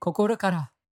感謝 ボイス 声素材 – Gratitude Voice
Voiceボイス声素材